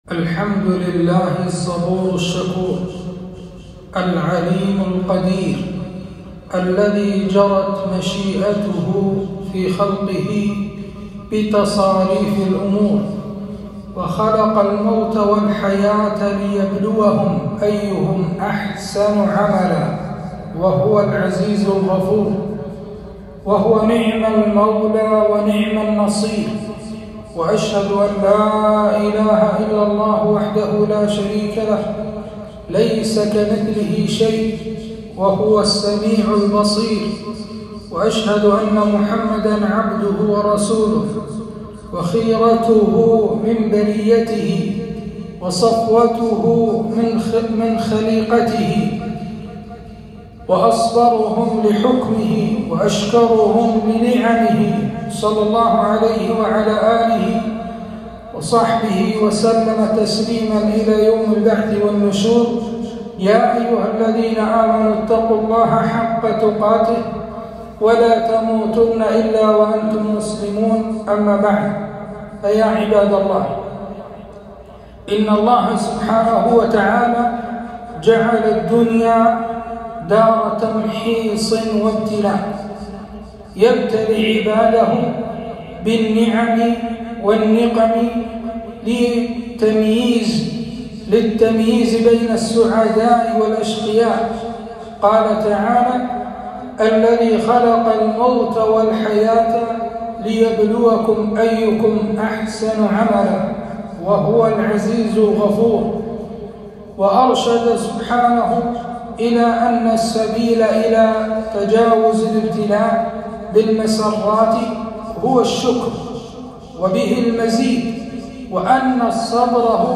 خطبة - حرمة الانتحار في الإسلام - دروس الكويت